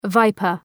Shkrimi fonetik {‘vaıpər}